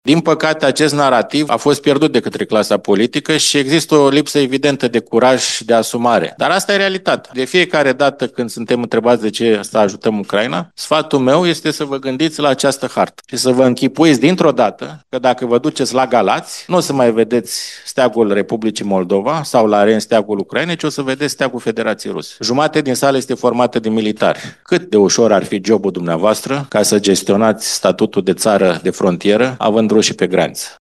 Nevoia ajutorului militar oferit Ucrainei de România a fost printre temele de discuție ale conferinței „Provocări de securitate în Balcani”, organizată la Timișoara.